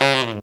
Index of /90_sSampleCDs/Best Service ProSamples vol.25 - Pop & Funk Brass [AKAI] 1CD/Partition C/TENOR FX2